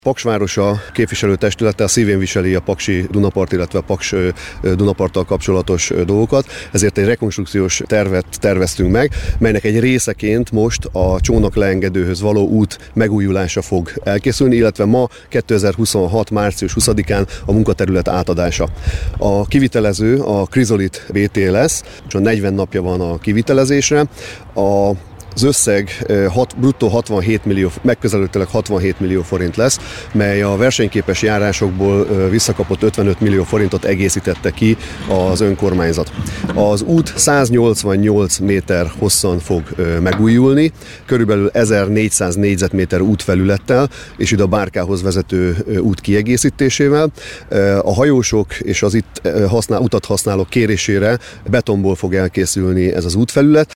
Megújul a buszpályaudvar melletti csónaklerakóhoz vezető út, erről Molnár József önkormányzati képviselő beszélt rádiónknak. Mint mondta a beruházás során 188 méter, megközelítőleg 1400 négyzetméter út újul meg mintegy 67 millió forintból.